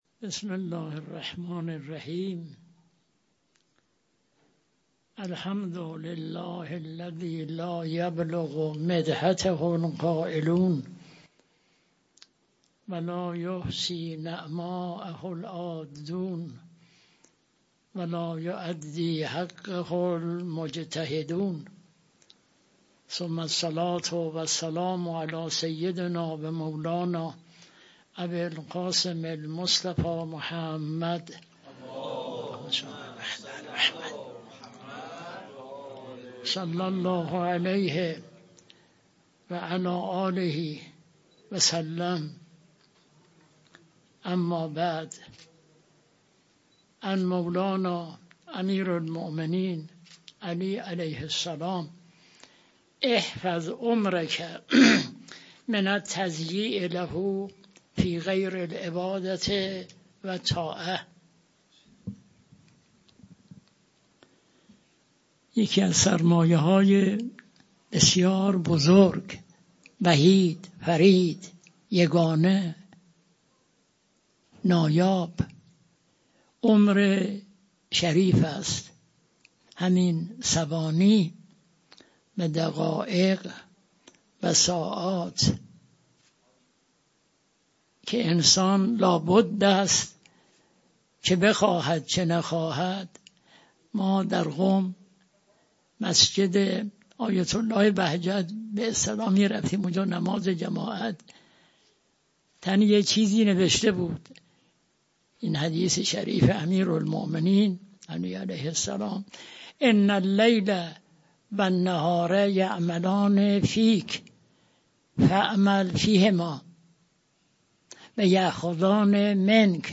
مدرسه امام خمینی در محضر استاد ۲۲۸ درس اخلاق آیت الله صدیقی؛ ۰۱ اردیبهشت ۱۴۰۳ در حال لود شدن فایل های صوتی...